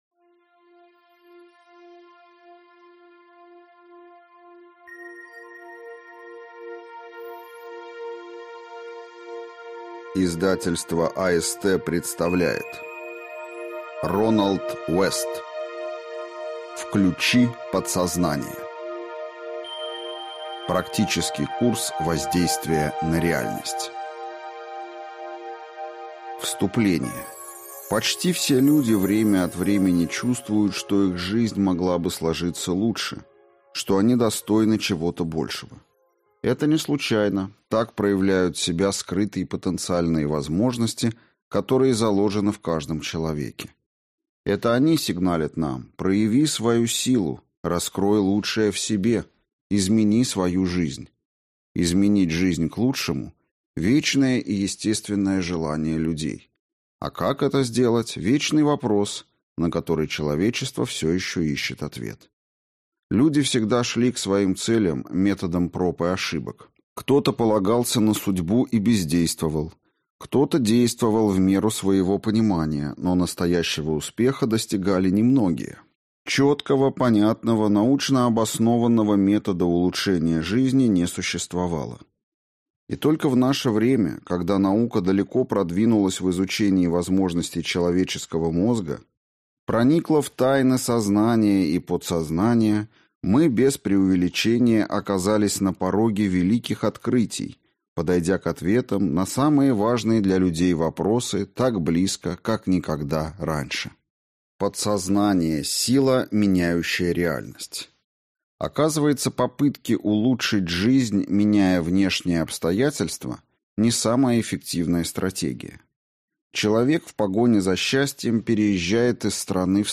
Аудиокнига Включи подсознание. Практический курс воздействия на реальность | Библиотека аудиокниг